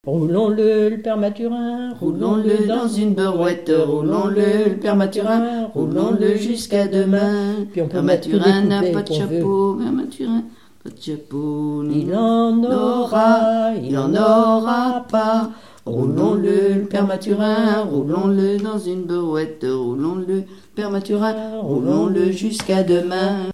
en duo
Genre énumérative
Pièce musicale inédite